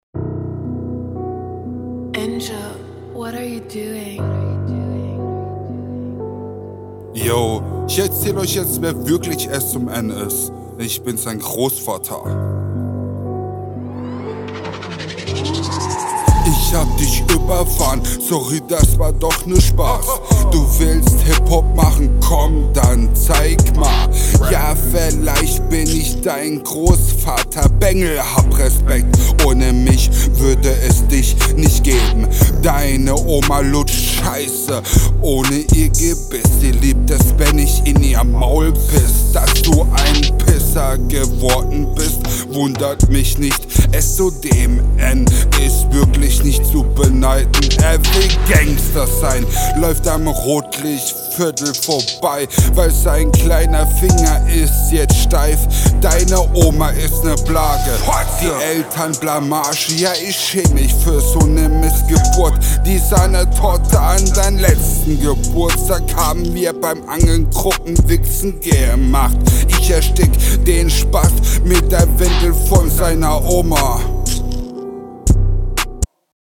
Flowlich auch hier träge, aber besser.